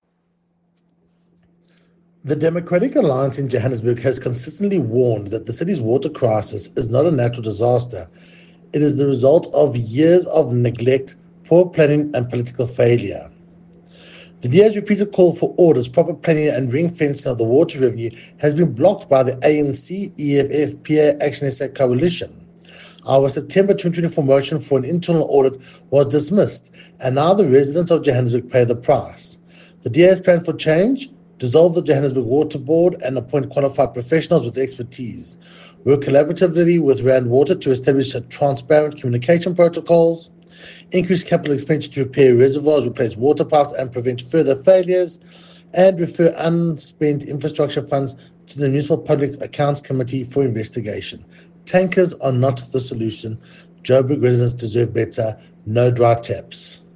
Note to Editors: Please find a soundbite by Cllr Tyrell Meyers